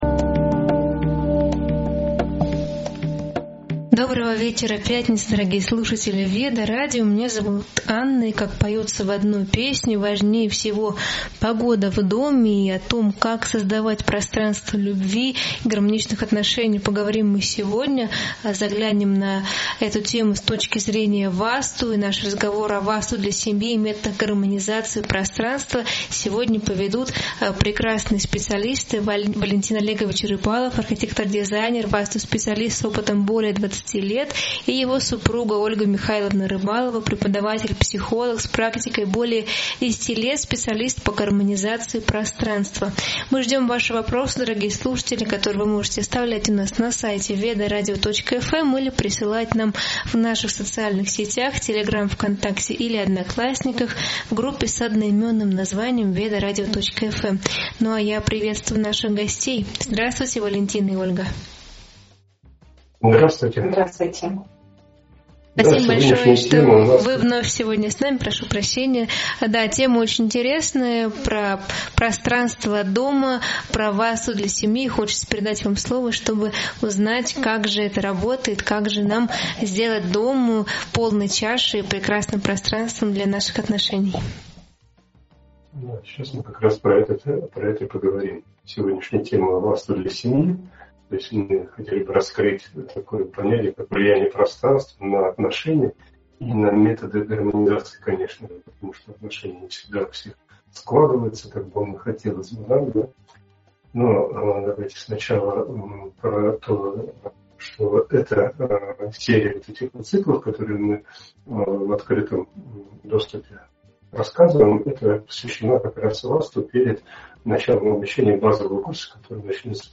В эфире обсуждается, как пространство дома влияет на здоровье, мышление и отношения. Раскрываются принципы Васту, значение чистоты, зонирования и энергетических потоков. Особое внимание уделяется влиянию семейной динамики и личного пространства на гармонию.